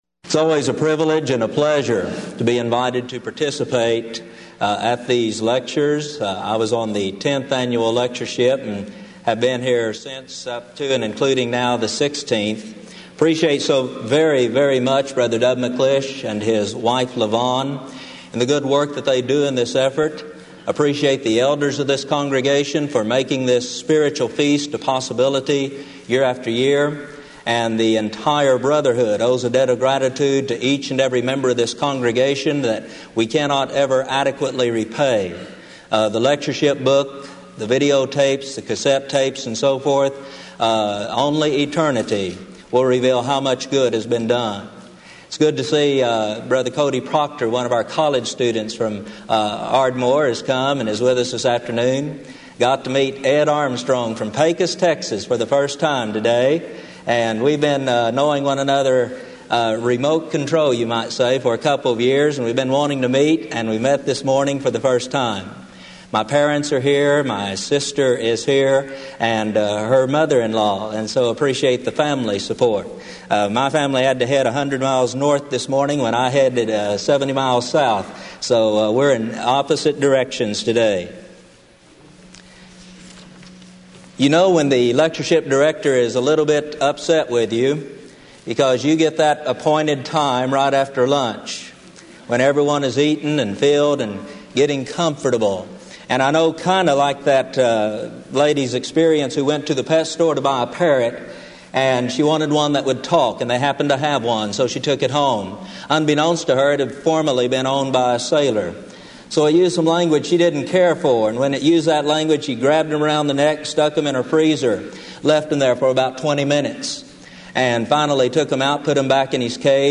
Event: 16th Annual Denton Lectures Theme/Title: Studies In Ephesians
lecture